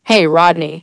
synthetic-wakewords
synthetic-wakewords / hey_rodney /ovos-tts-plugin-deepponies_Billie Eilish_en.wav
ovos-tts-plugin-deepponies_Billie Eilish_en.wav